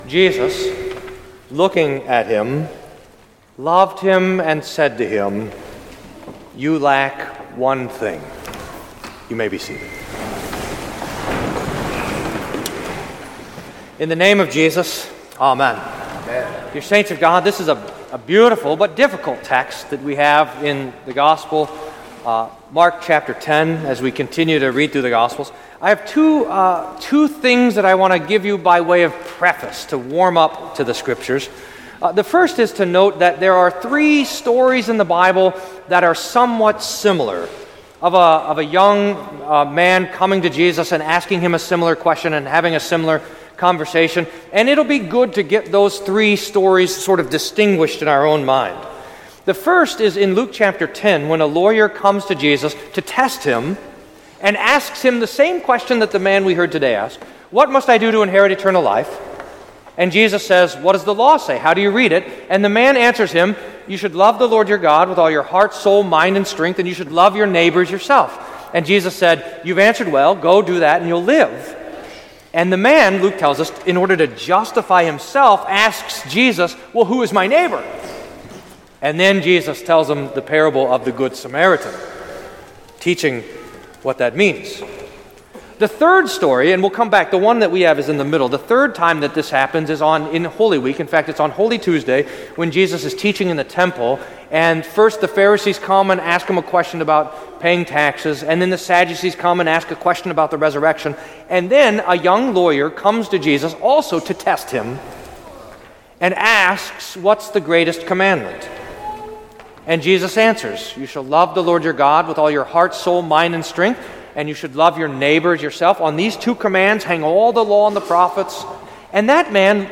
Sermon for Twentieth Sunday after Pentecost